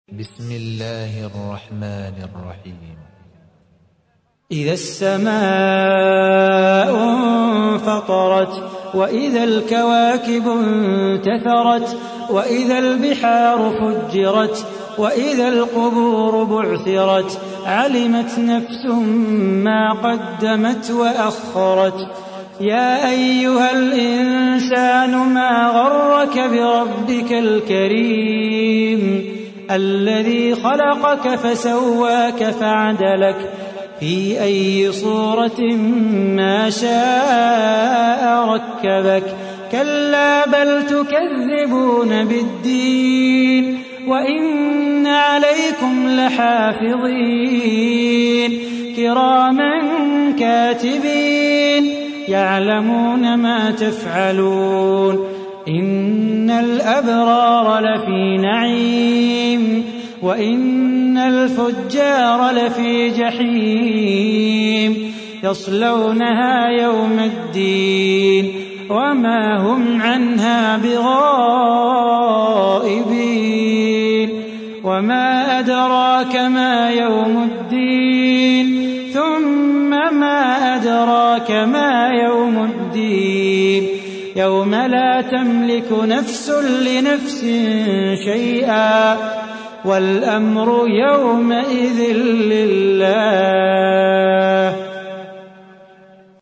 تحميل : 82. سورة الانفطار / القارئ صلاح بو خاطر / القرآن الكريم / موقع يا حسين